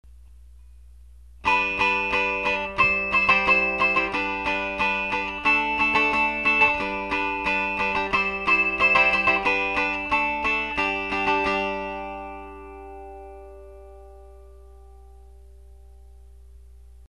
A collection of old-time and traditional strum-style songs .